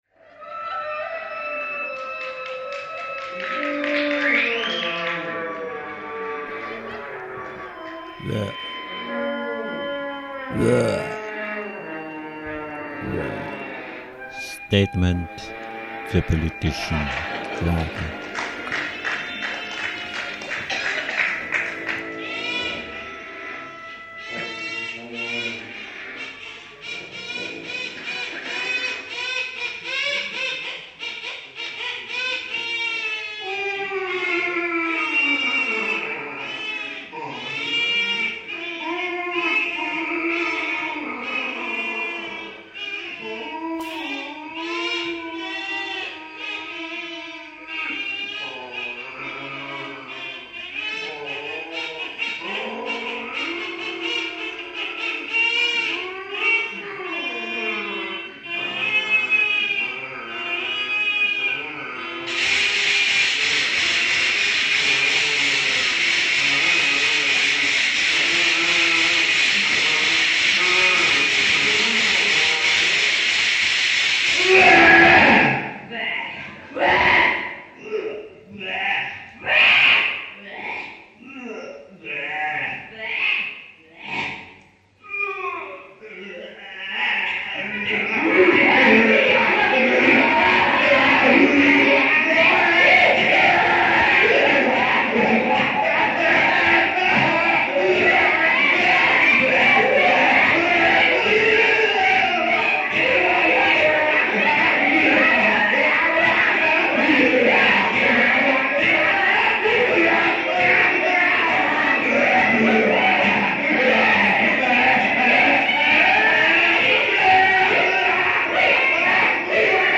Wuuääähhhh! Statement zur politischen Lage, Live" 1996.